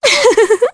Laias-Vox_Happy3_jp.wav